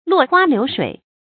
注音：ㄌㄨㄛˋ ㄏㄨㄚ ㄌㄧㄨˊ ㄕㄨㄟˇ
落花流水的讀法